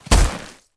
Sfx_creature_snowstalker_walk_08.ogg